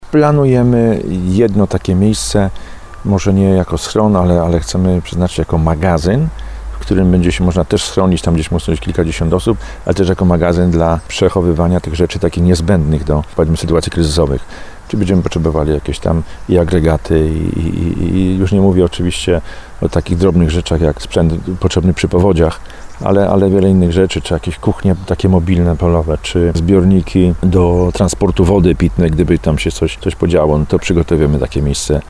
Jak tłumaczy burmistrz Żabna Tomasz Kijowski, tworzenie miejsc schronienia jest podyktowane Ustawą o Ochronie Ludności i Obronie Cywilnej.